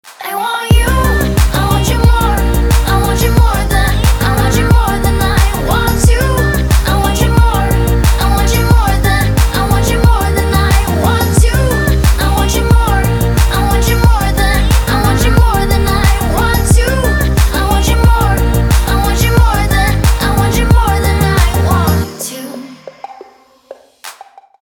dance
club